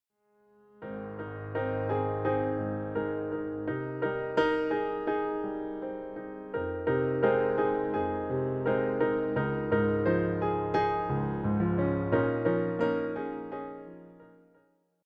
solo piano
Just calm and relaxing renditions of these well-known songs.